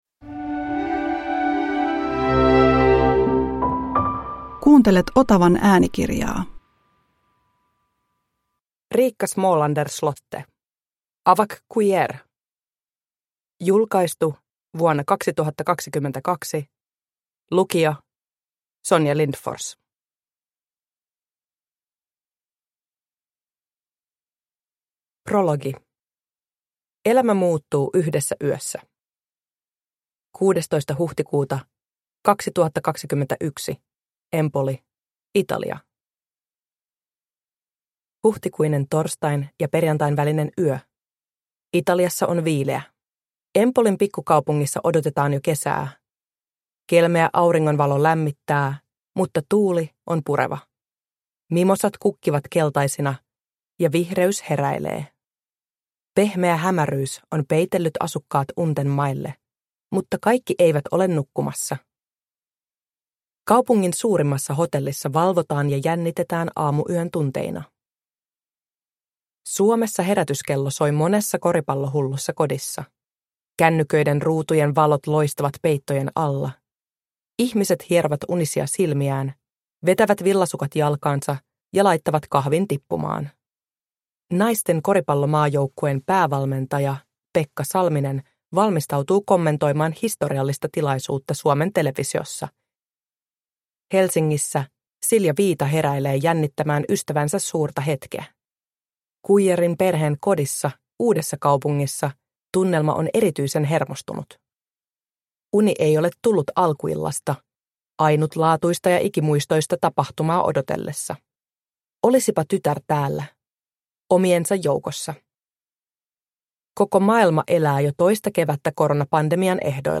Awak Kuier – Ljudbok – Laddas ner